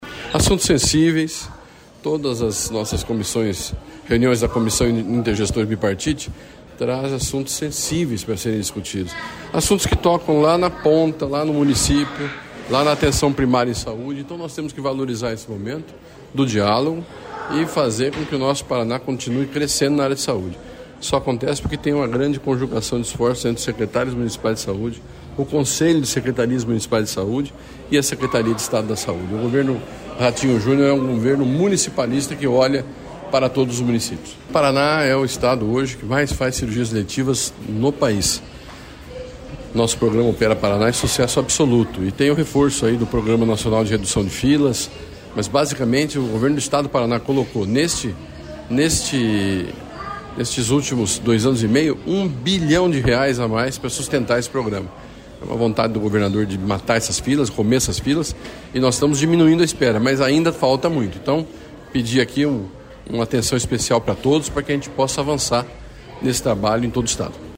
Sonora do secretário Estadual da Saúde, Beto Preto, sobre as 2.100 cirurgias por dia pelo Opera Paraná